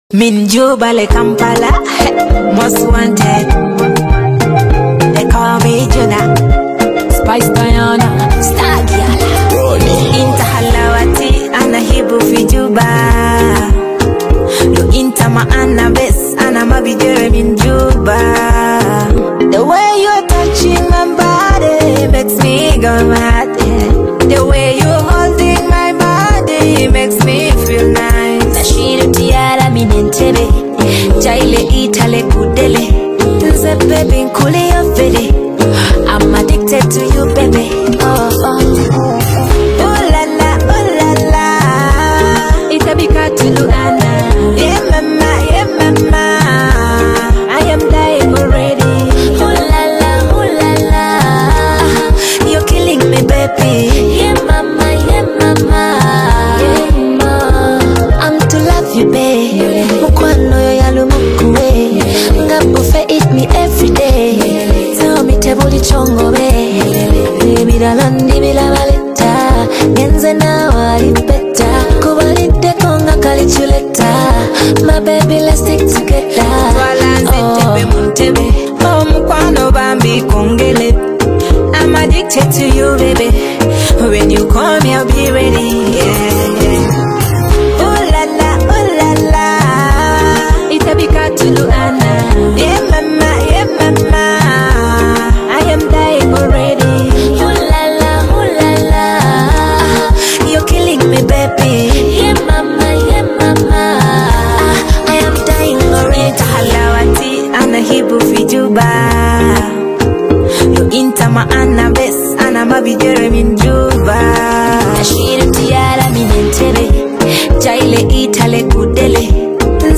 vibrant Ugandan Afro-Pop/Dancehall collaboration
catchy lyrics and infectious dance rhythms
creating a feel-good anthem for fans across the region.